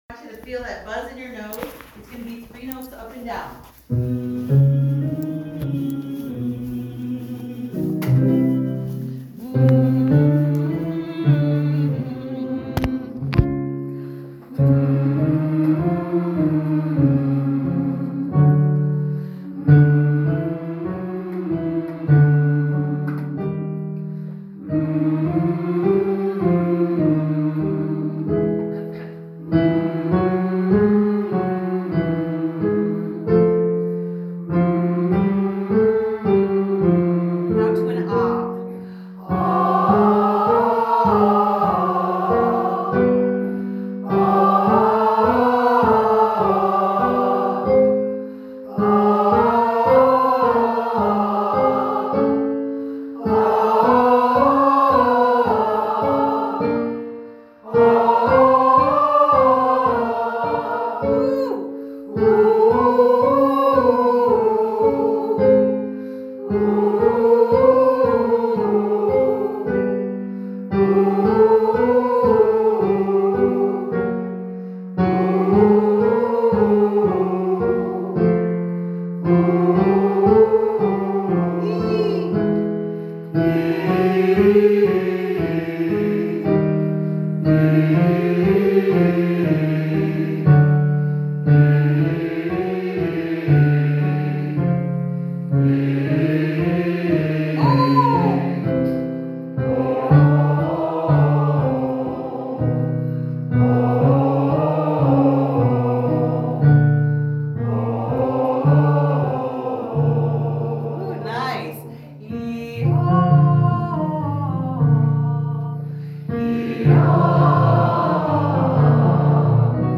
Vocal Warm-Ups
Vocal Warm-Up
all voice types